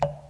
drip.ogg